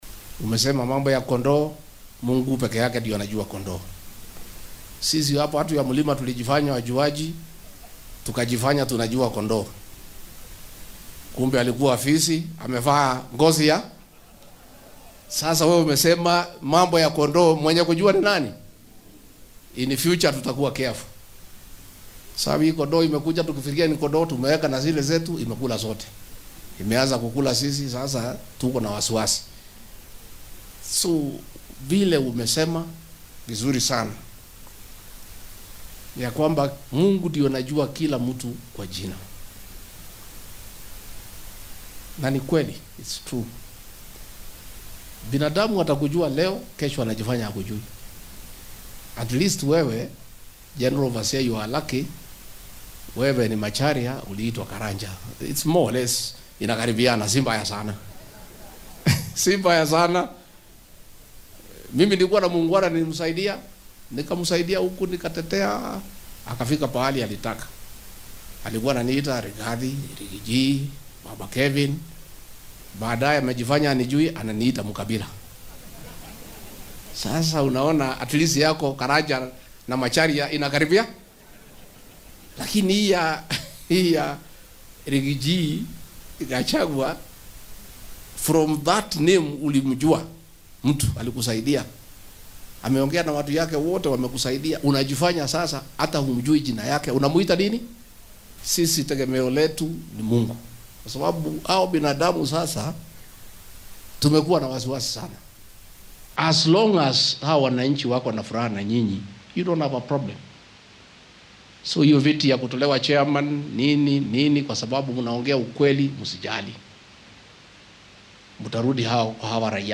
Madaxweyne ku xigeenkii hore ee dalka Rigathi Gachagua ayaa shacabka ku nool bartamaha waddanka ee Mt. Kenya ka codsaday inay cafis u fidiyaan isagoo tani u aaneeyay wax uu ku tilmaamay inay ku marin habaabiyeen dadweynaha ka hor doorashadii guud ee 2022-kii. Isagoo ka hadlayay Kaniisadda Full Gospel Church ee magaalada Ol-Kalou ee ismaamulka Nyandarua ayuu Gachagua sheegay inuu diyaar u yahay inuu cafiyo kuwii u geystay dhibaatooyinkii keenay in xilka laga tuuro.